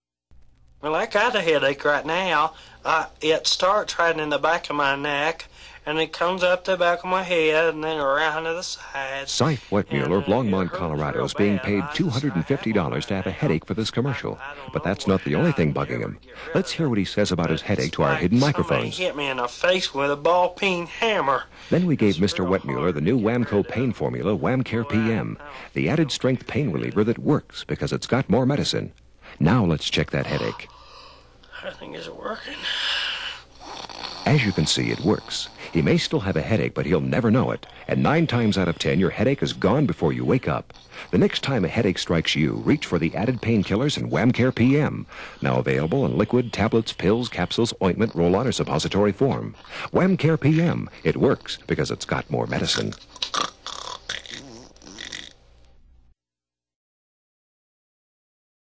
He has used the studio recording equipment and software at KONA to dub the LP tracks sans clicks and pops!.